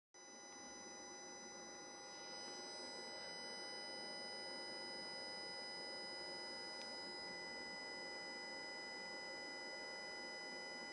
当电源的PWM 1KHz 30%时，电流波形正常，但是人耳能够听到滋滋的噪音，请问可以如何解决。
还有没有别的办法，这个声音听起来挺刺耳的。（附件是噪音录音）